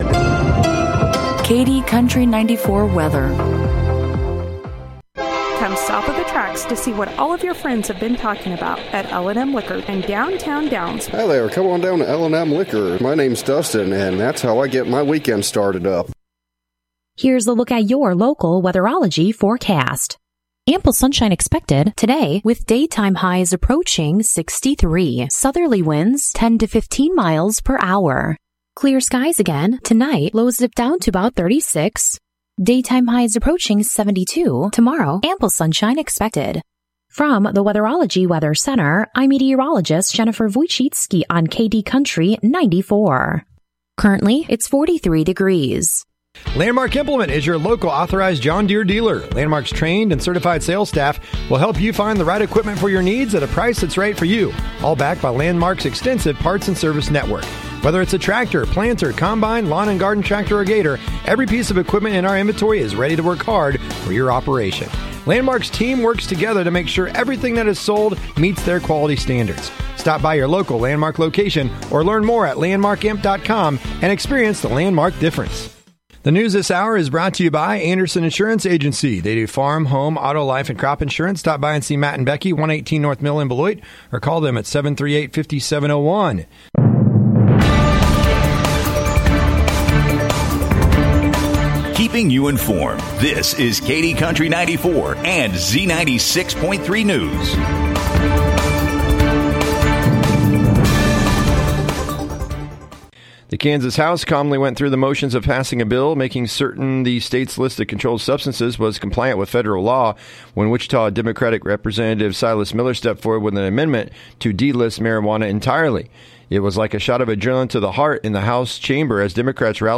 🎙 KD Country 94 Local News, Weather & Sports – 3/1/2024